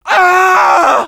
Voice file from Team Fortress 2 French version.
Scout_painsevere01_fr.wav